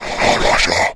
spawners_mobs_mummy_spell.4.ogg